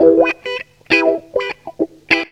GTR 58 EM.wav